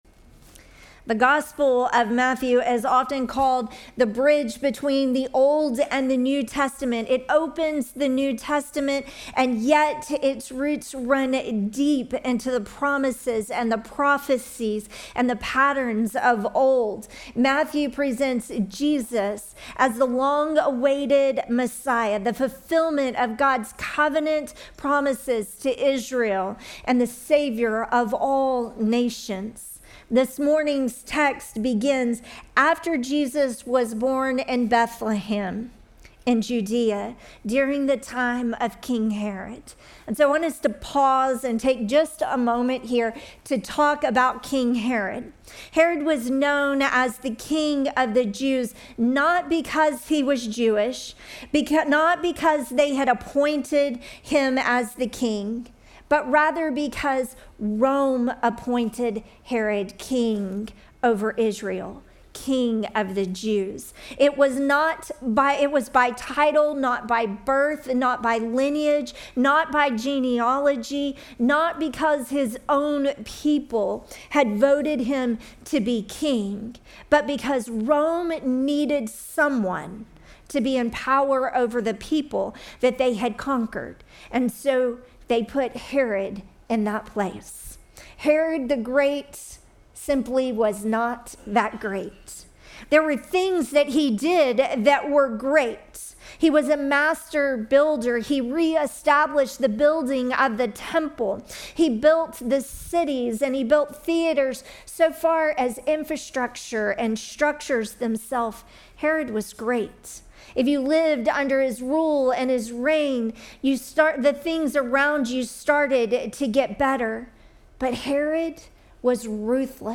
Sermon text: Matthew 2:1-12